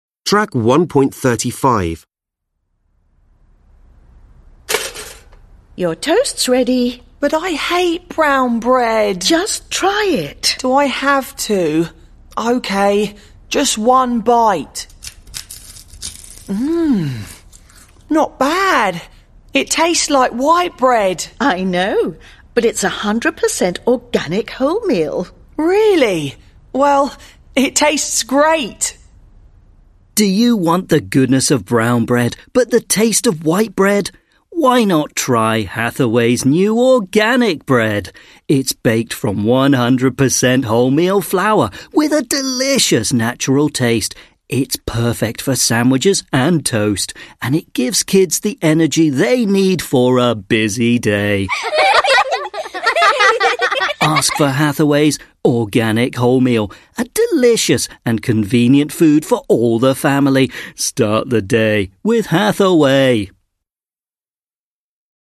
You are going to hear an advertisement for bread.